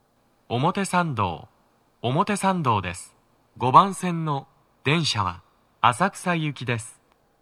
スピーカー種類 TOA天井型
5番線 上野・浅草方面 到着放送 【男声
gomotesando5toucyaku.mp3